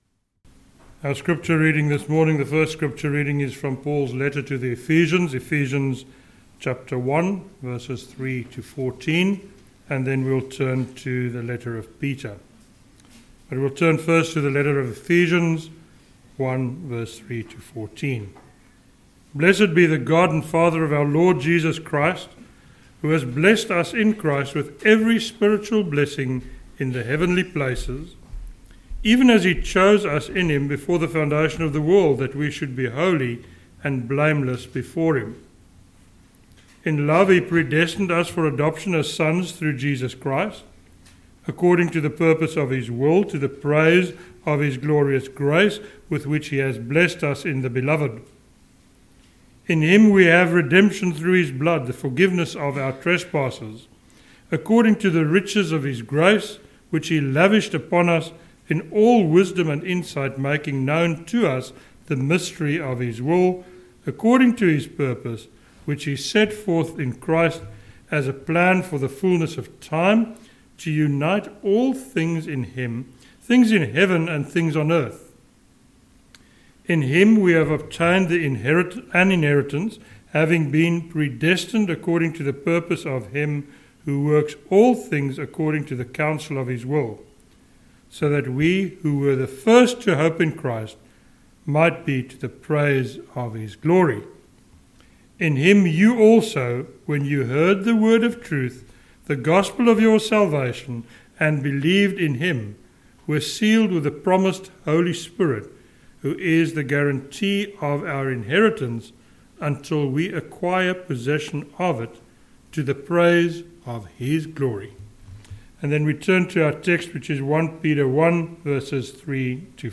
a sermon on 1 Peter 1:3-5